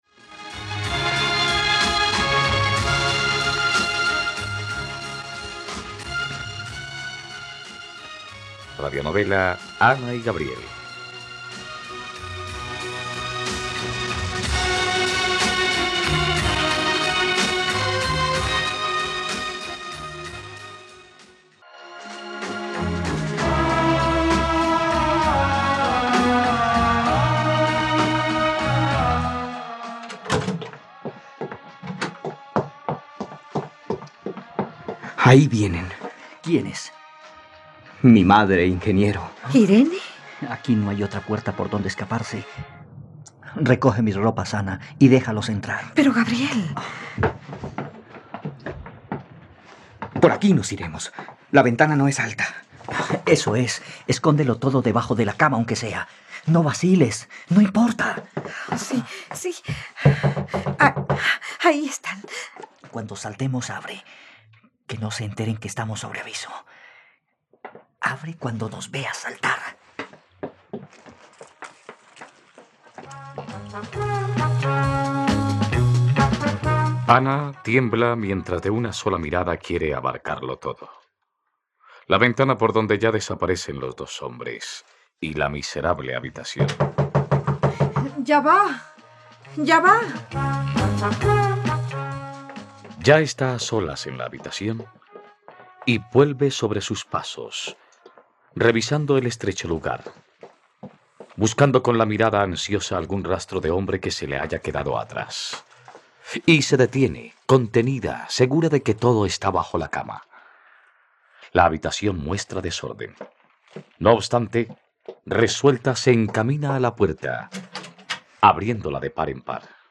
..Radionovela. Escucha ahora el capítulo 117 de la historia de amor de Ana y Gabriel en la plataforma de streaming de los colombianos: RTVCPlay.